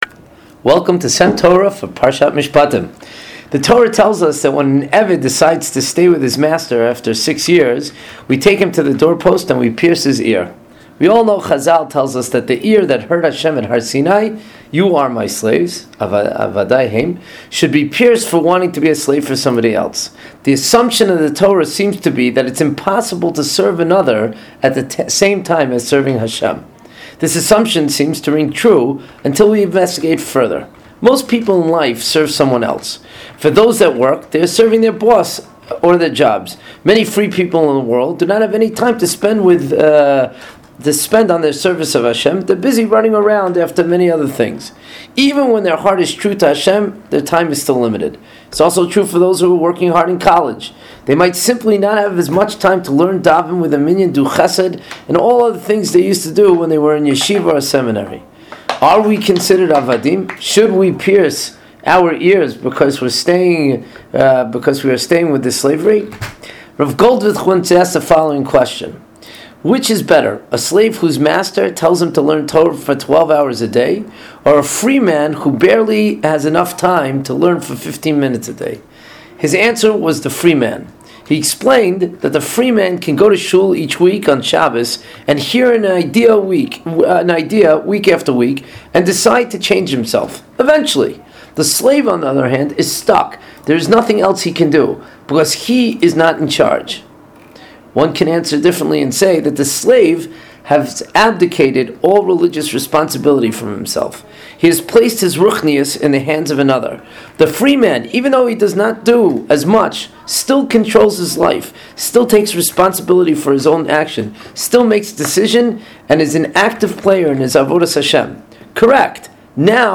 S.E.M. Torah is a series of brief divrei Torah delivered by various members of the faculty of Sha’alvim for Women.